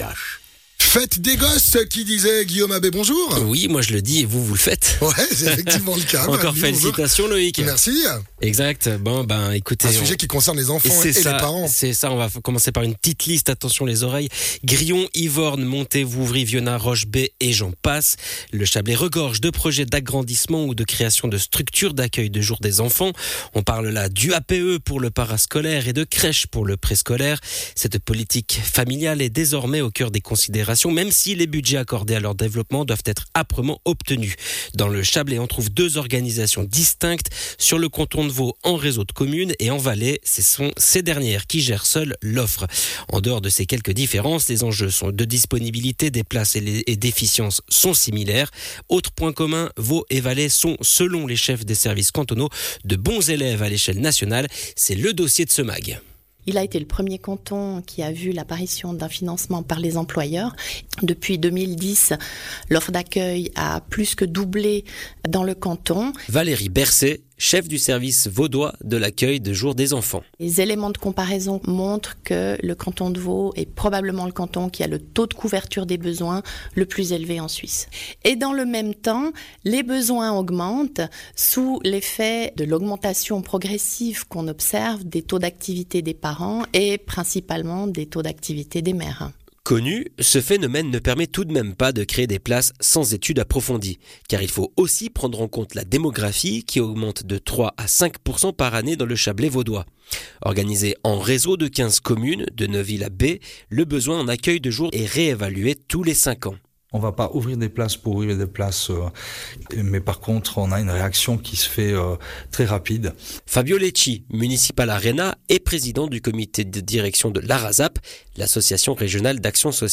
Intervenant(e) : Multi-intervenants